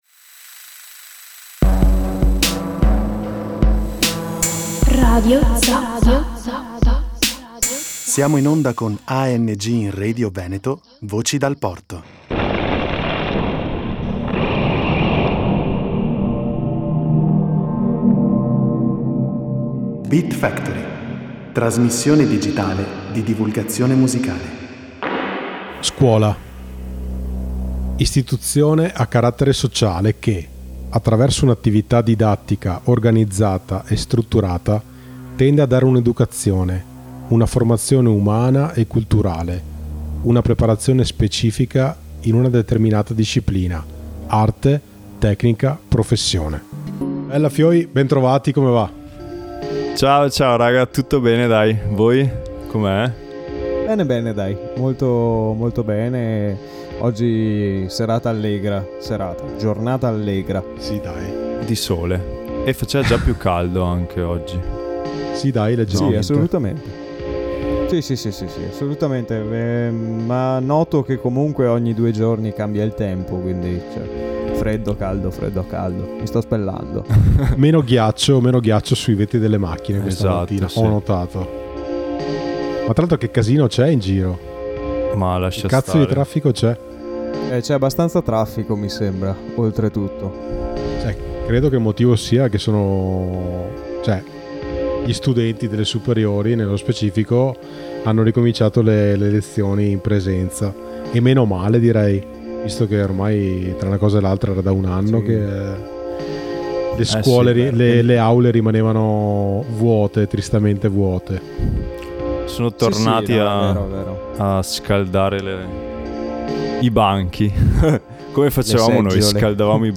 Il traffico mattutino, le corse inutili per non entrare in ritardo e gli ascolti edulcoranti degli anni della scuola dell’obbligo ci hanno portato ad una playlist davvero importante.